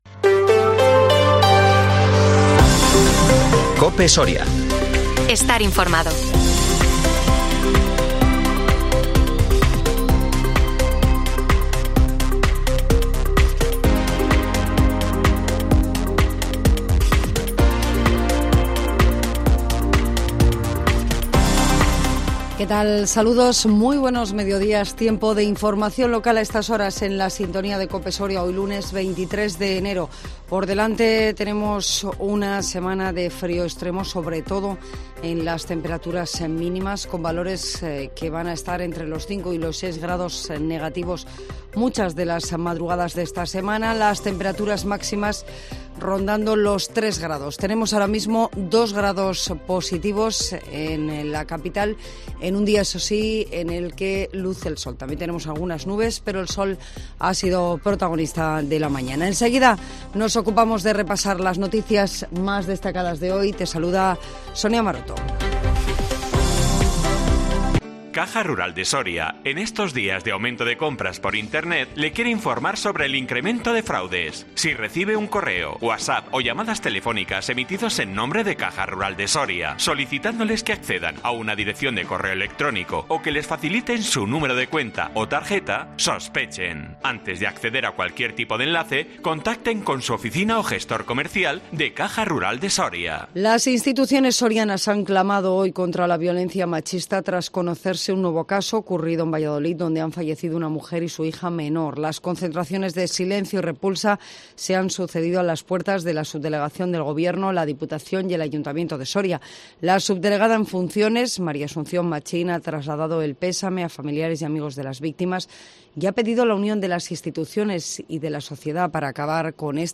INFORMATIVO MEDIODÍA COPE SORIA 23 ENERO 2023